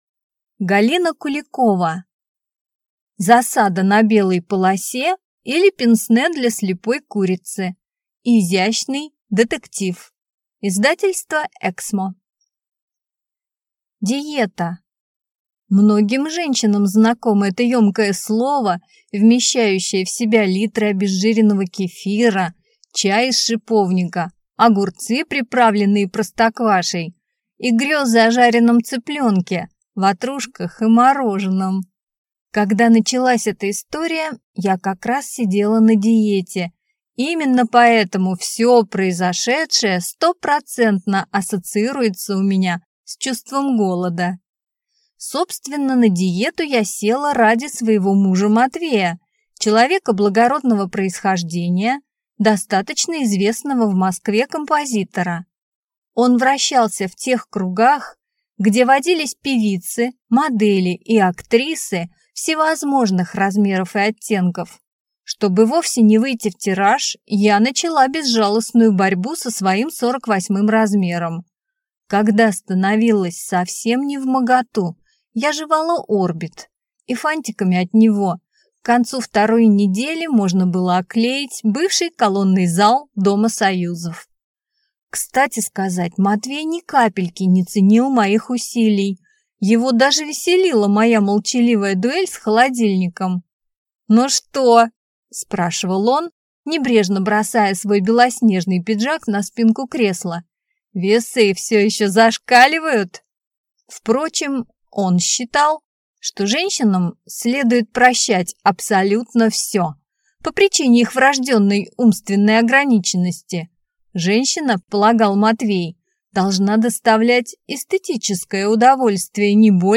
Аудиокнига Засада на белой полосе, или Пенсне для слепой курицы | Библиотека аудиокниг